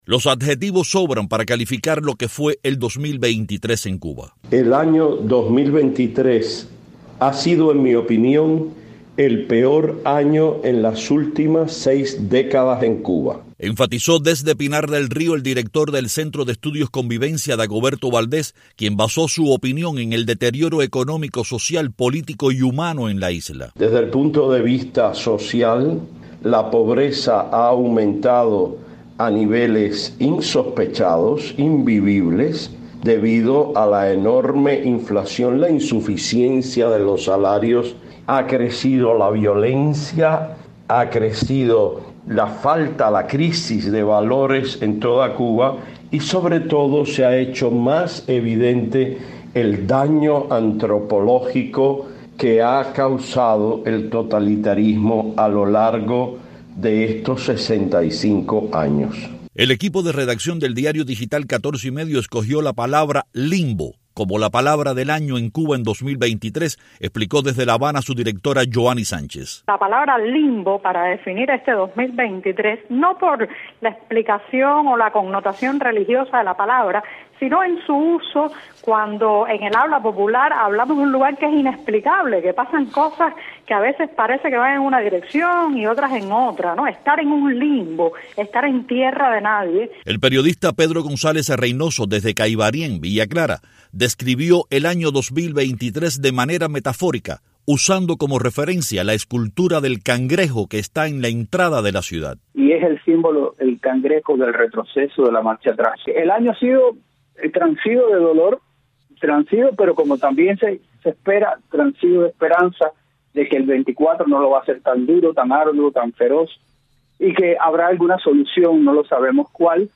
Los entrevistados por Martí Noticias se refirieron al agravamiento de la crisis económica, al éxodo sin precedentes, a la creciente falta de libertades y a la ola de violencia que atemoriza a los cubanos de un extremo a otro de la isla.